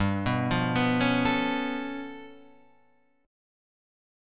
G9b5 chord